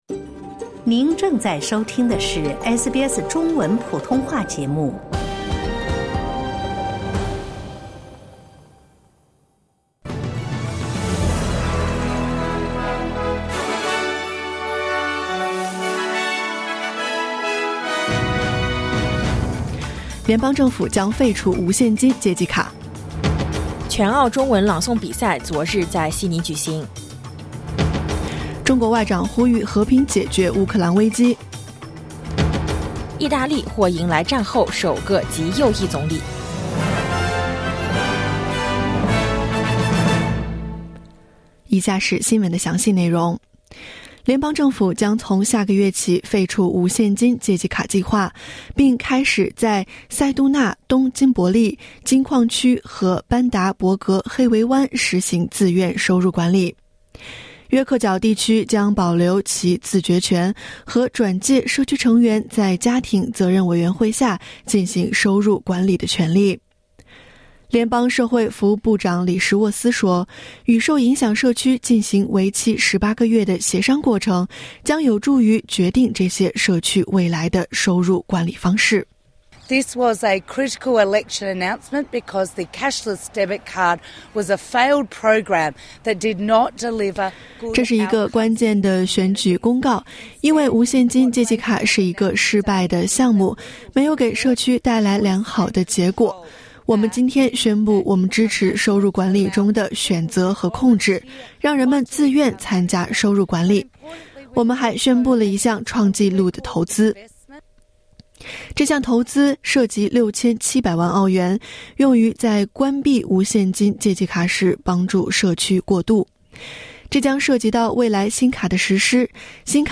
SBS早新闻（2022年9月25日）